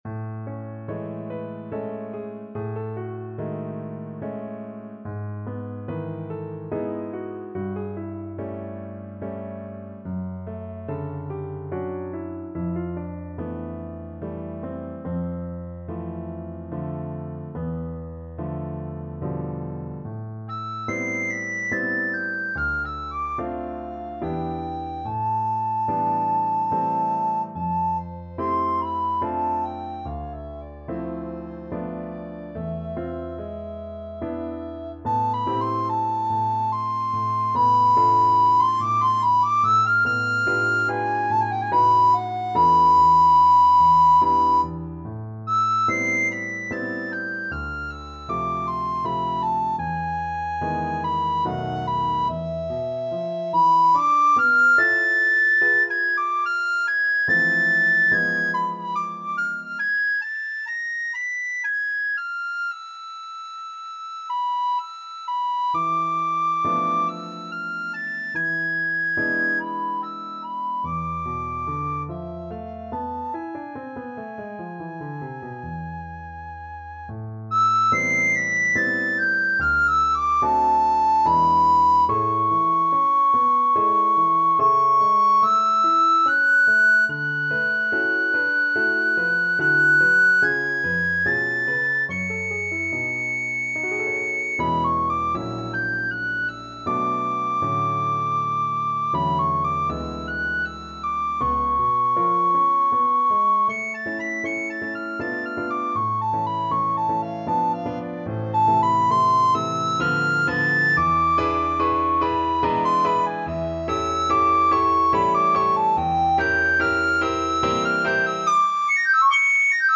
Classical Silva, Patápio Evocacao Soprano (Descant) Recorder version
A minor (Sounding Pitch) (View more A minor Music for Recorder )
Andante =72
Classical (View more Classical Recorder Music)